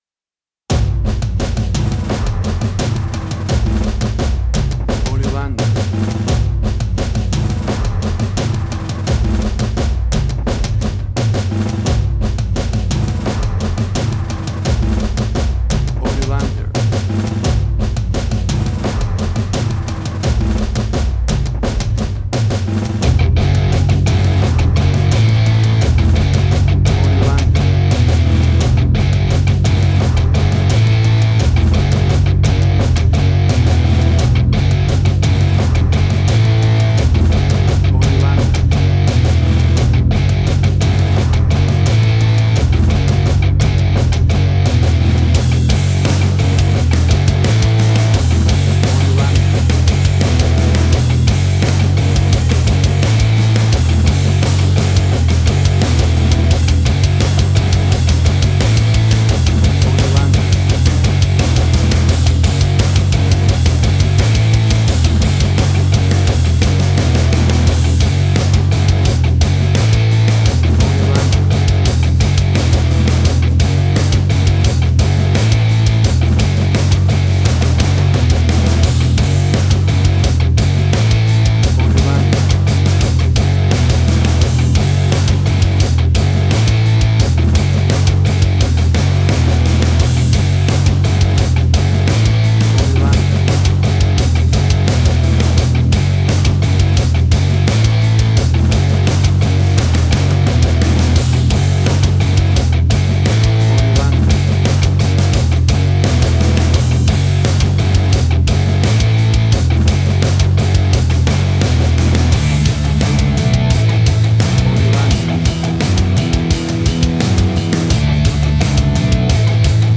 Tempo (BPM): 86